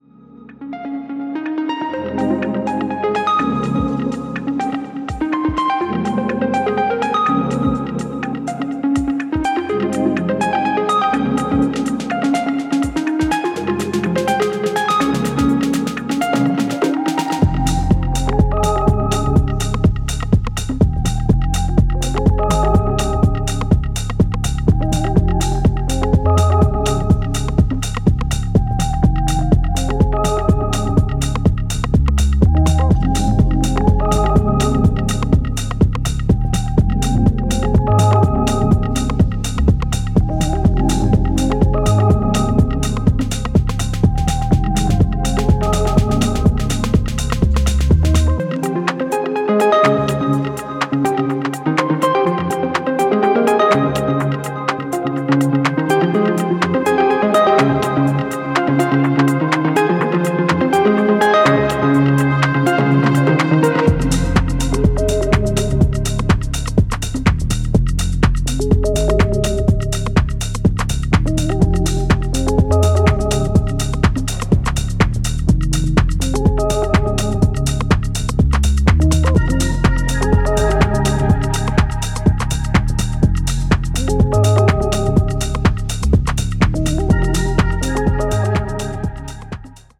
音数が増えすぎずドリーミーに進行するところにも好感が持てます。